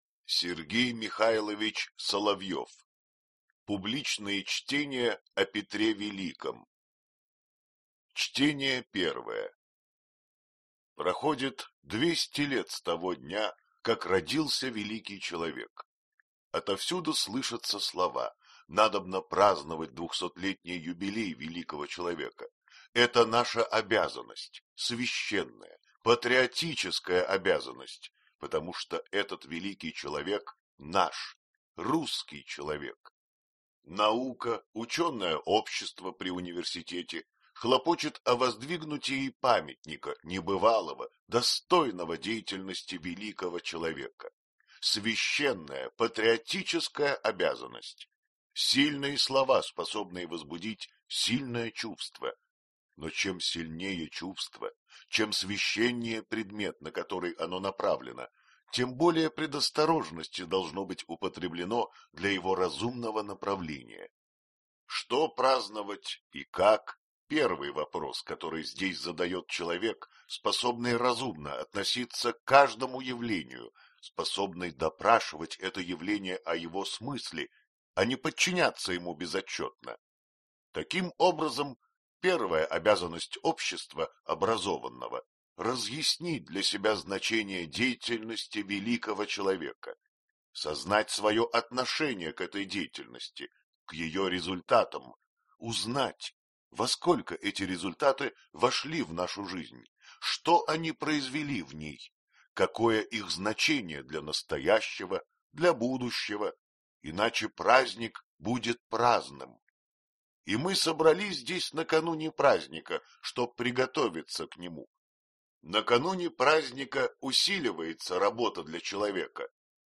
Аудиокнига Публичные чтения о Петре Великом | Библиотека аудиокниг